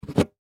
Взяли термос со стола в ладонь